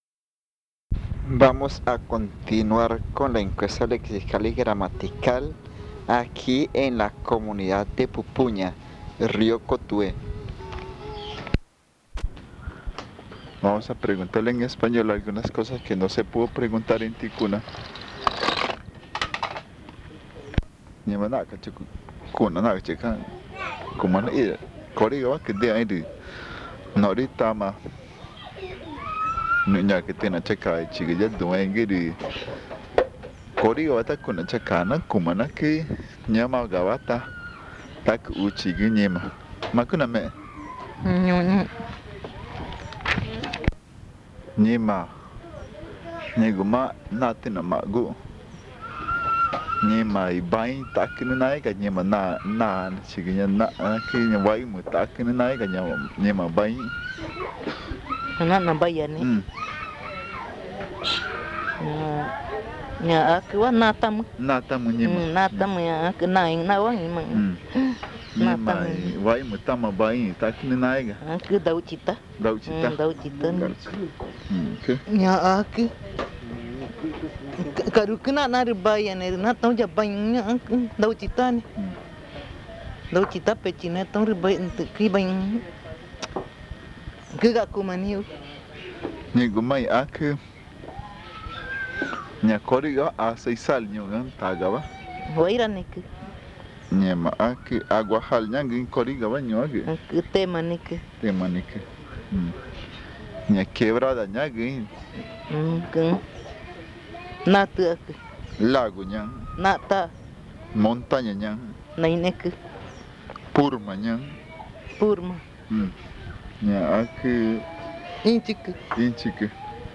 Encuesta léxica y gramatical en Pupuña (Río Cotuhé) - casete 3
Este casete es el tercero de una serie de cuatro casetes grabados en Pupuña.
El audio contiene los lados A y B.